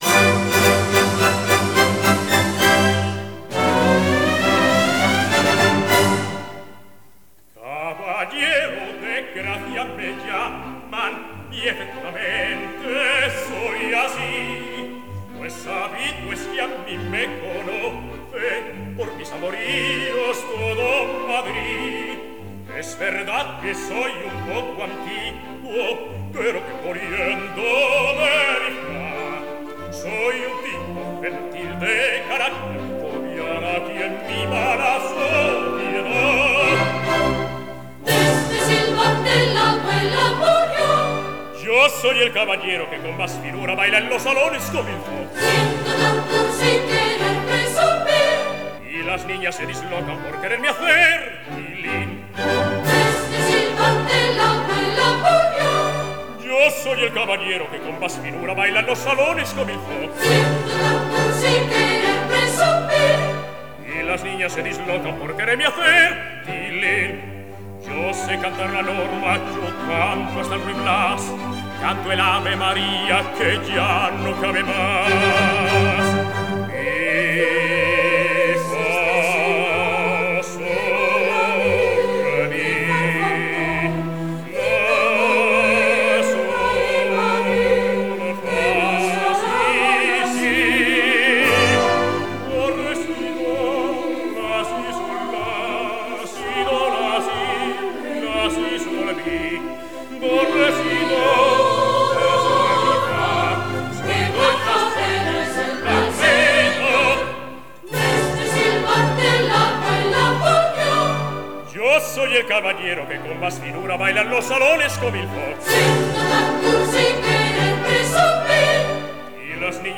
Canto
Ópera
Orquesta